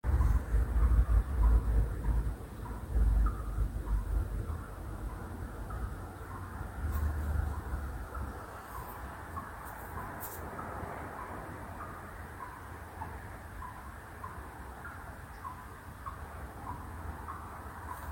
E’ venuto anche il secondo tecnico, il misteriosi ticchettio pare provenire da zona al di là della Bellinzona bassa perché è un rimbombo che si propaga via aria. Non si comprende se sia causato da perdita d’acqua o sia un rumore “elettrico” (allarme scarico, ventola che si sta rompendo…).
Per l’occasione il residente ha inviato un secondo audio (anche in questo caso abbiamo alzato il più possibile il volume):